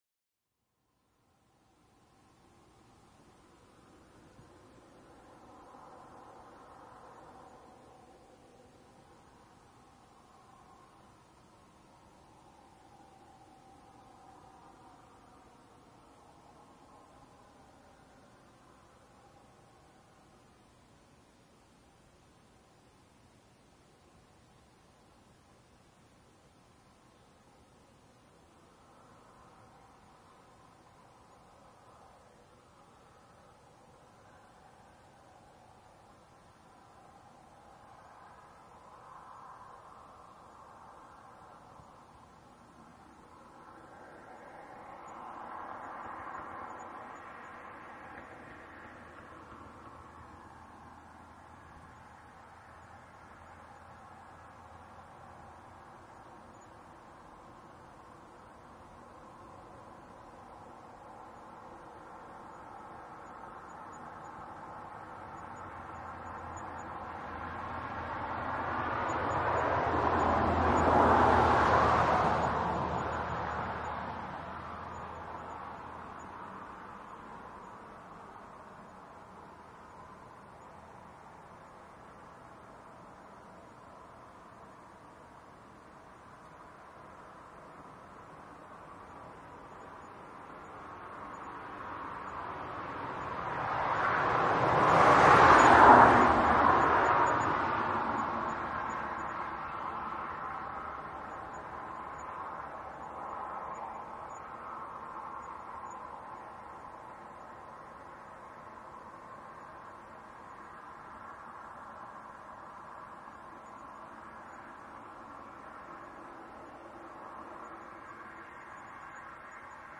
0001 汽车驶过 RL LR
描述：两辆车在高速公路上行驶。第一辆车从右到左，第二辆车从左到右。
Tag: 高速公路 现场记录 汽车 driveby